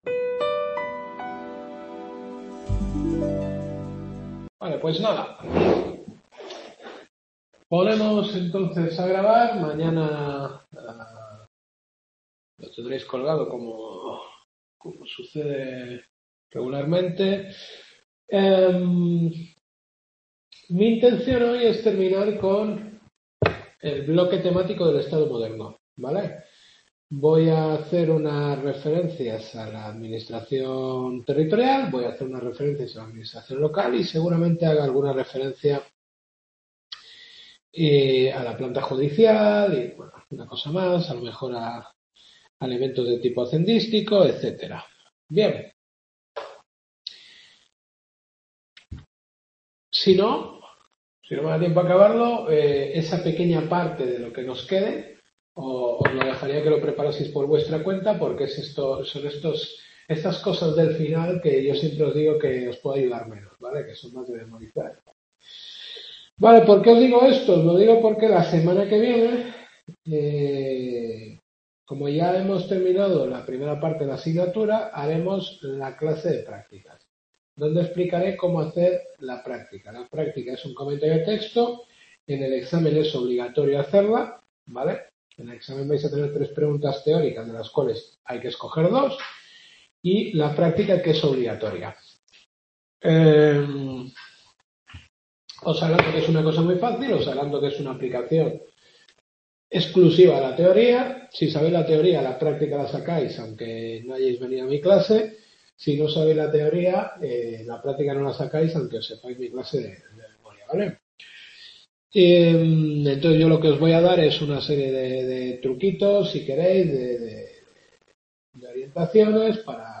Quinta Clase.